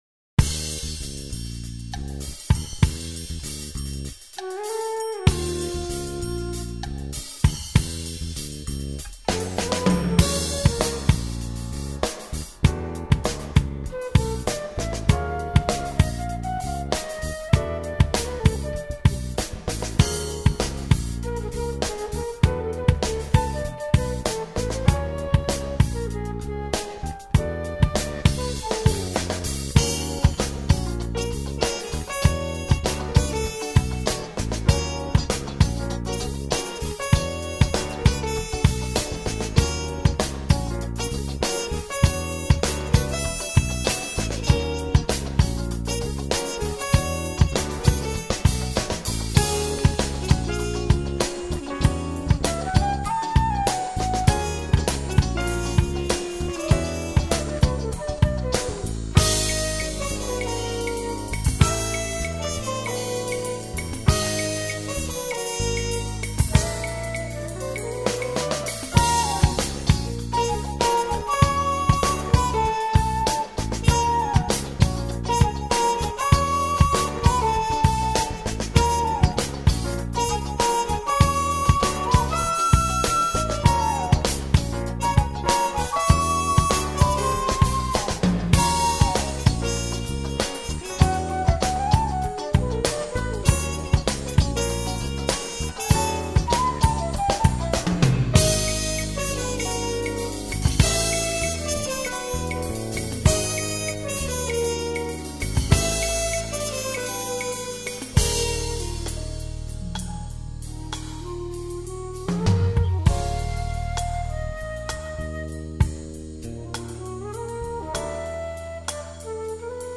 tromba, composizione, arrangiamento, direzione